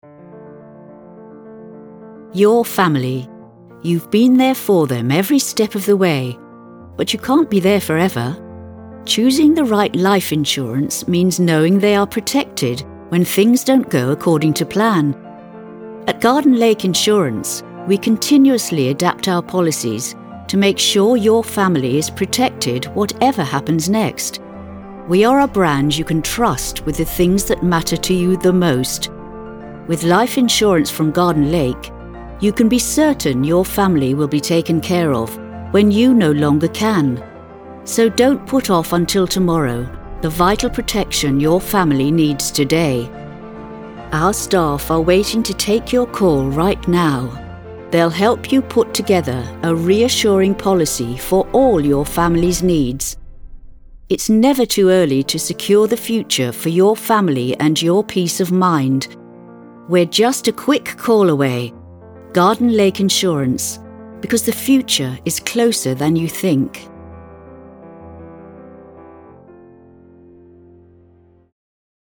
Female
English (British)
Adult (30-50), Older Sound (50+)
Radio Commercials